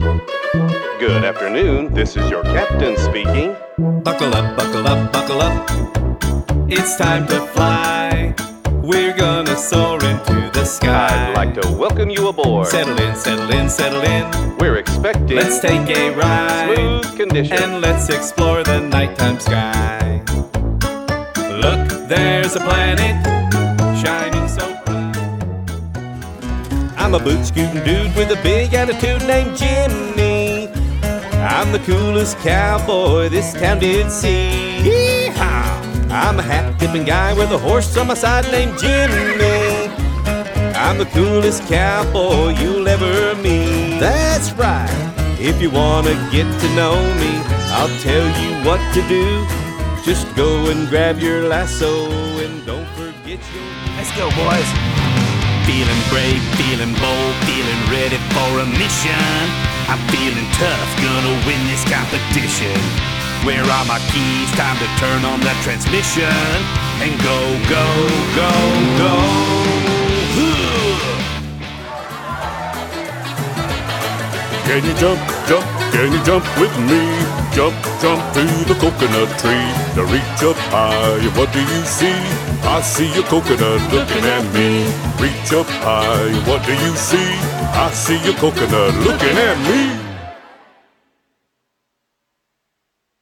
Male
Singing
Kids Media Singing Demo Reel